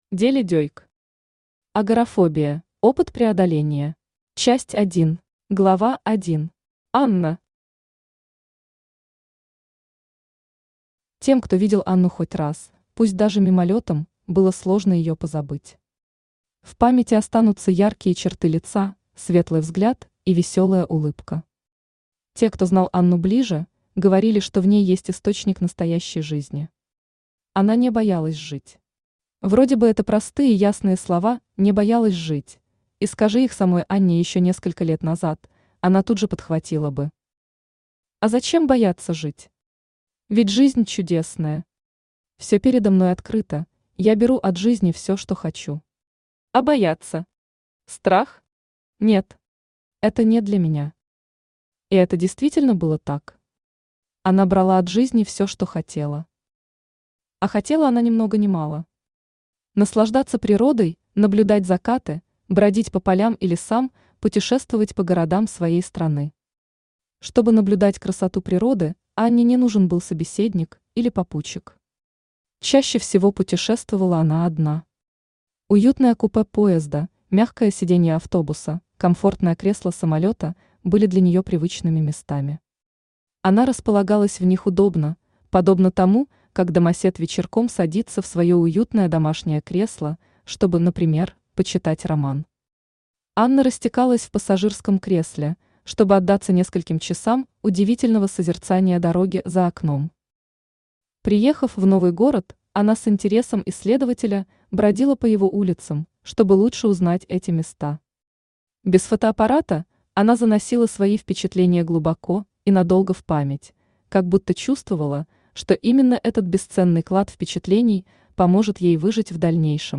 Аудиокнига Агорафобия: опыт преодоления | Библиотека аудиокниг
Aудиокнига Агорафобия: опыт преодоления Автор Дели Дейк Читает аудиокнигу Авточтец ЛитРес.